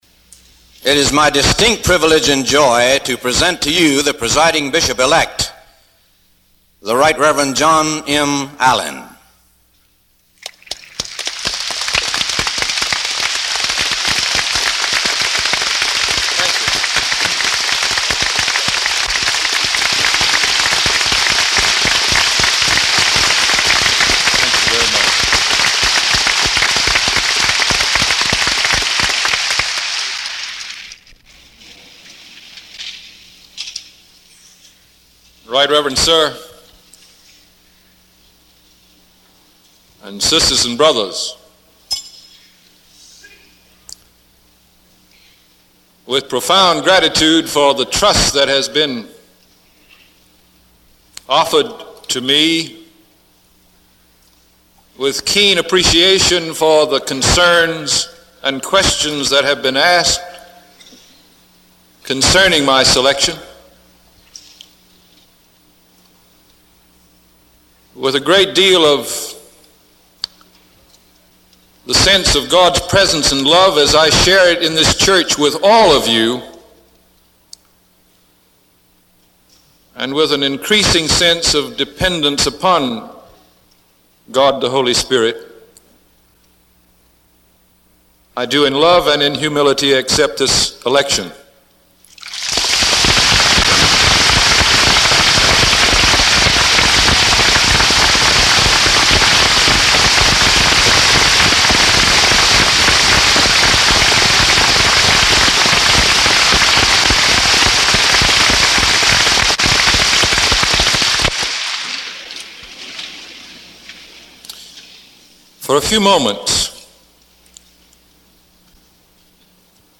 Allin Presiding Bishop Election Acceptance Speech